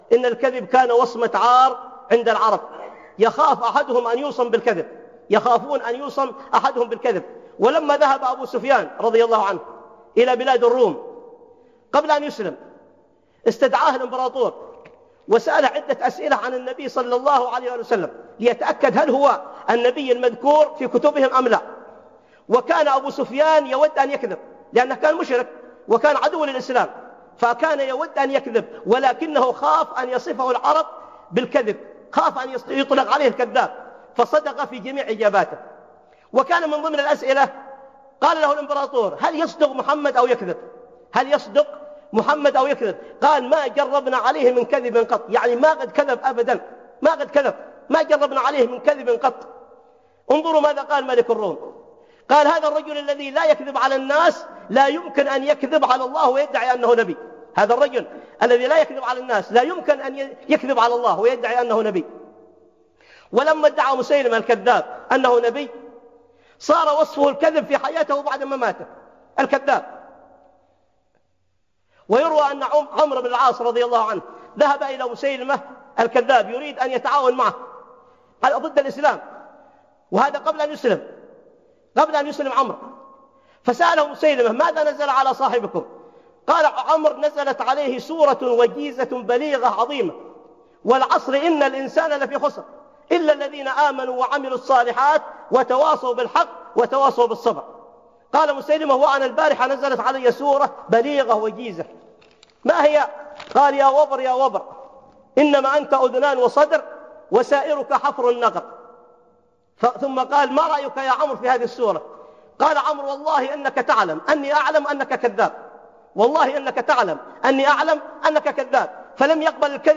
الكذب عار على الرجل - خطب